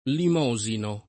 elemosinare v.; elemosino [ elem 0@ ino ]